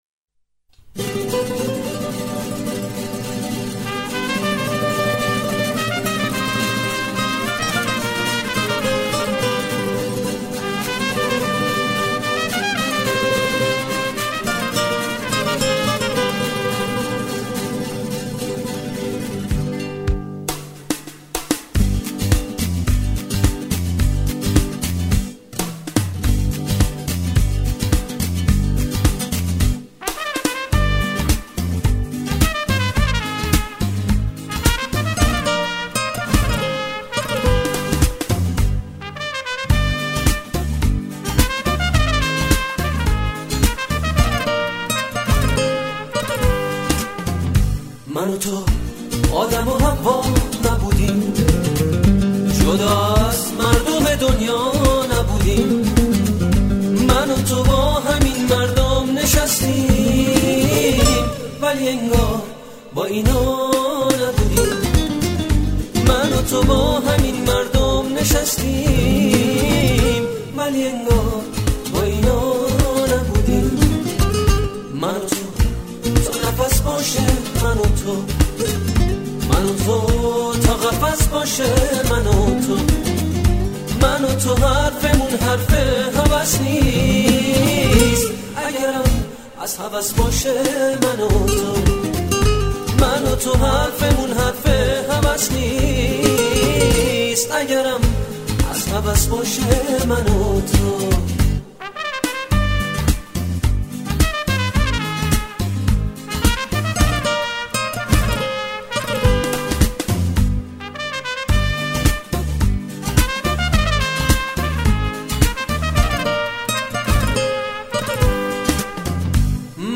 اهنگ قدیمی